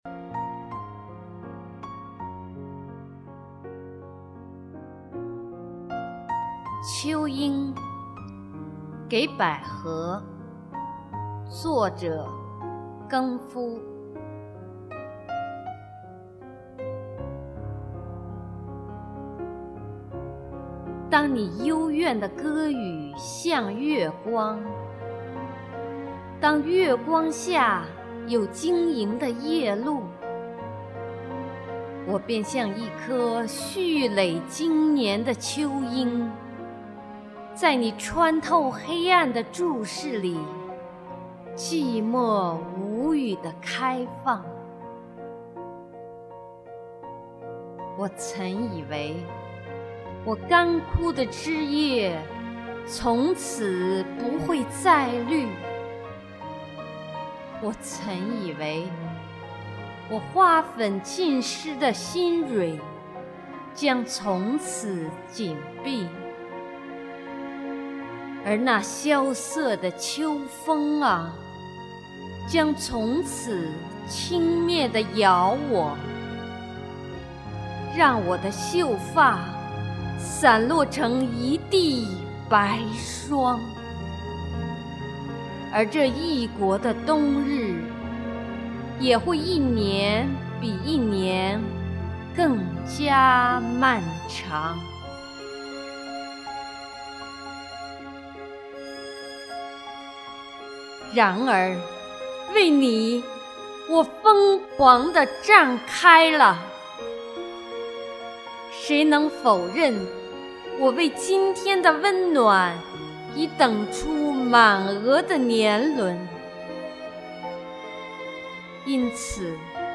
贴一个原来朗诵的吧。:)
很喜欢妳的朗诵，音色柔美、音质厚实、情感殷切。
朗诵得真好，感情恰到好处，听起来真是享受。